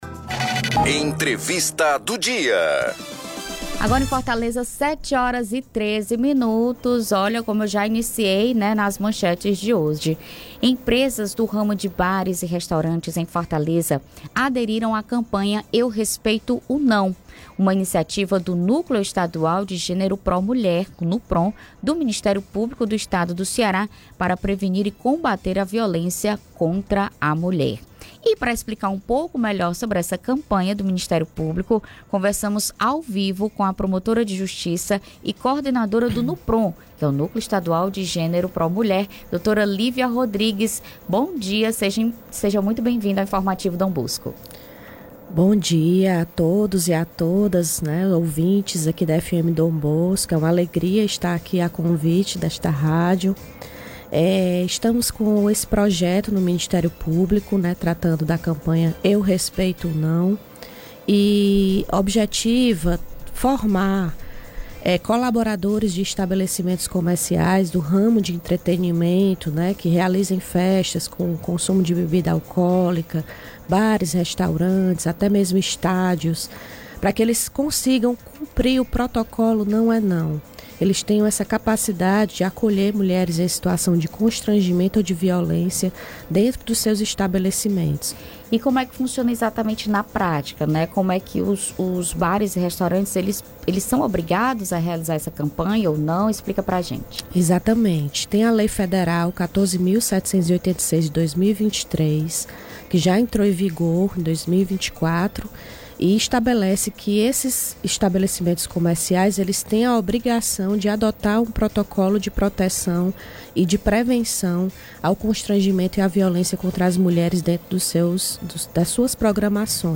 ENTREVISTA-2708.mp3